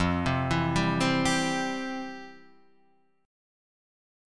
F Major 7th Suspended 2nd